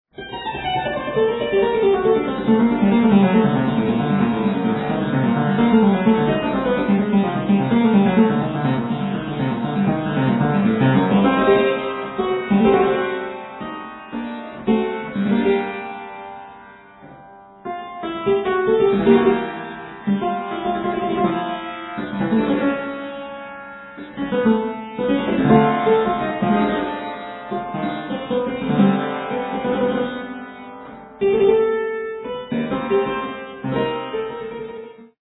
performed on clavichord